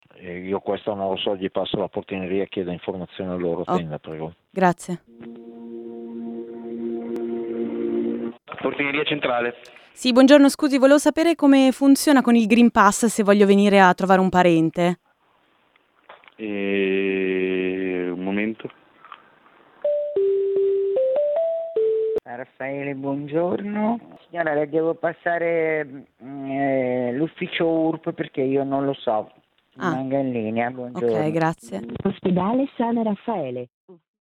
Radio Popolare ha provato a vedere com’è la situazione chiamando alcuni ospedali lombardi, questo è quello che abbiamo scoperto.